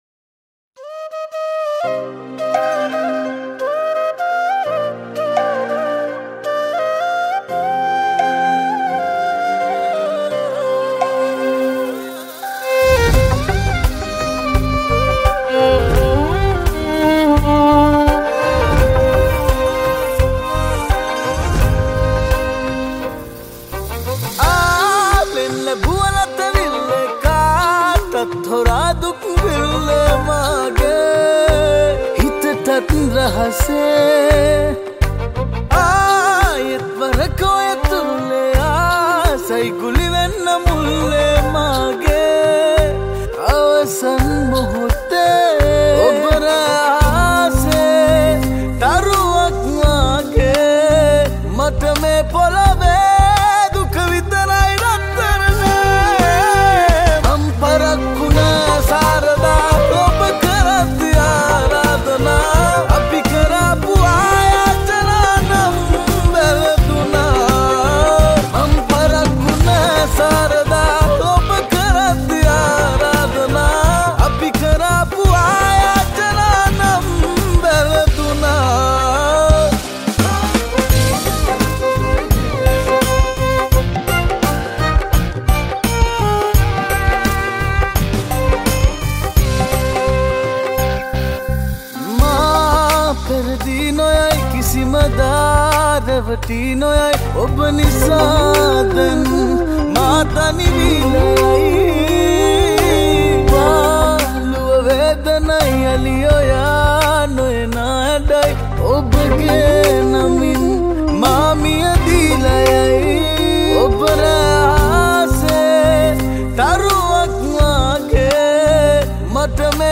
High quality Sri Lankan remix MP3 (5.2).
remix